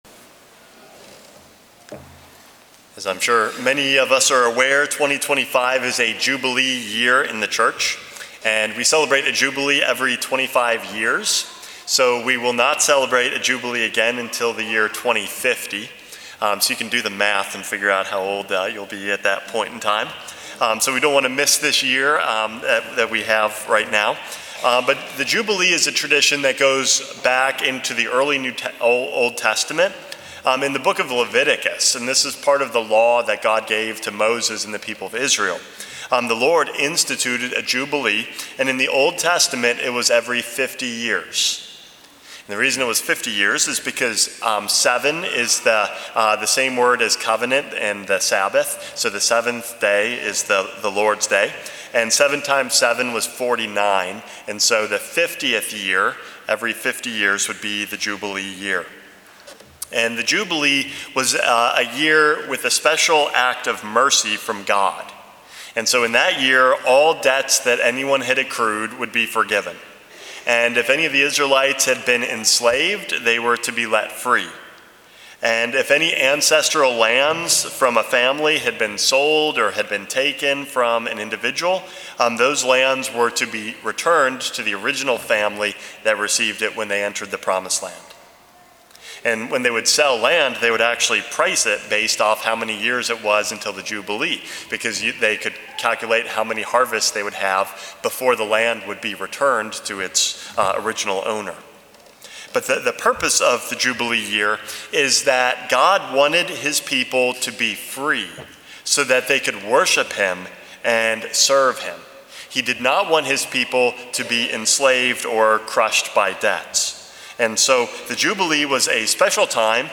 Homily #460 - Signs of Hope